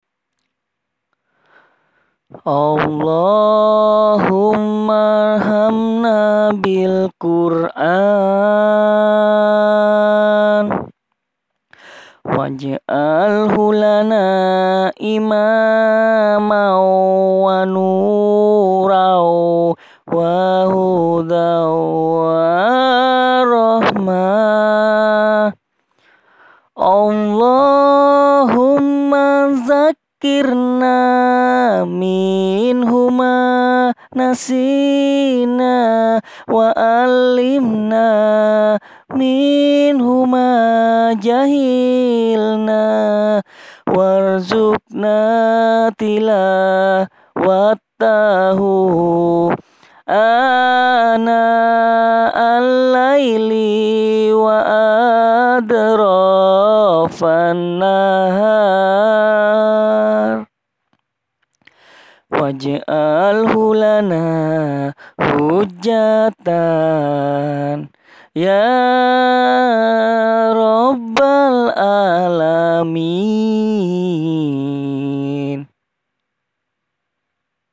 Maaf kalau suaranya jelek, ini asli saya rekam sendiri bacaan pujian yang biasanya saya pakai.
Mohon maaf sebelumnya jikalau suara saya jelek :).